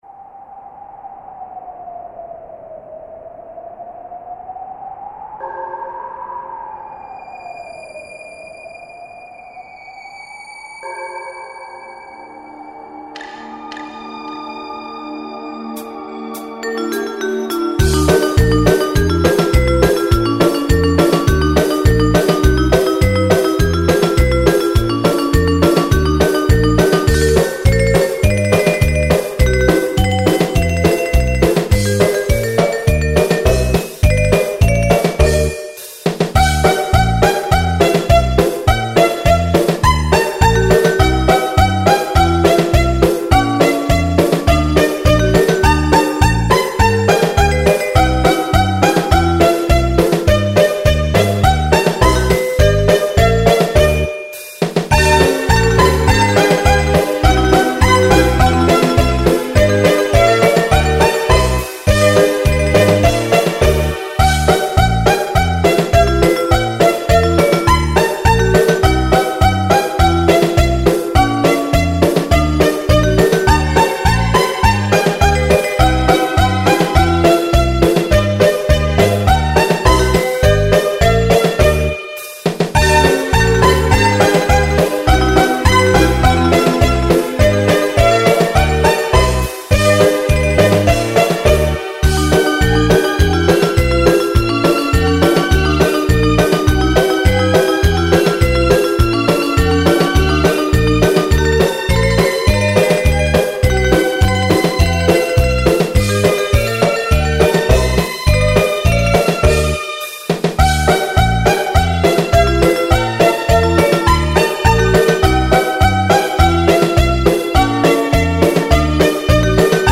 Веселая песенка для концерта или для детских праздников